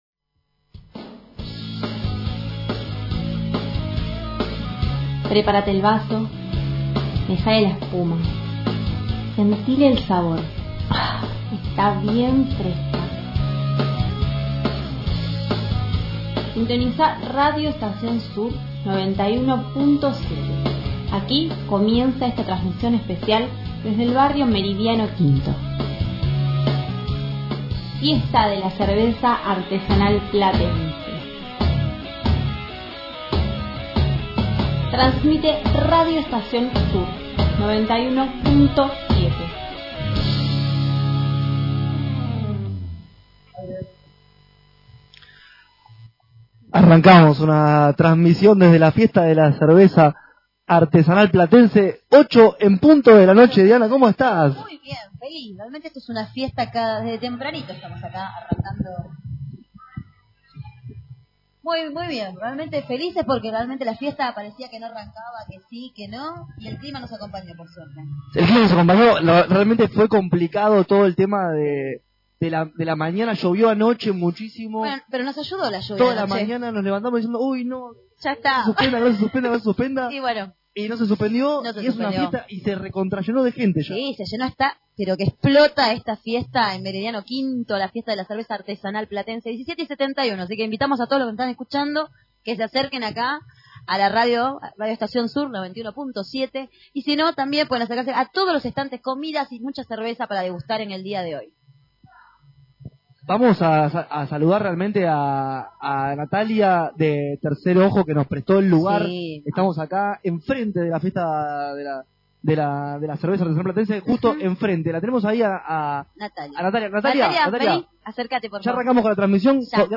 Transmisión especial de Radio Estación Sur en la Fiesta de la Cerveza
Desde el bar el Bar Tercer Ojo y organizado por Otro Hermoso Día en el Útero, Radio Estación Sur transmitió en vivo desde la Fiesta de la Cerveza Artesanal en Meridiano V. Productores de cerveza artesanal y músicos locales pasaron por el micrófono y contaron su trabajo y experiencia.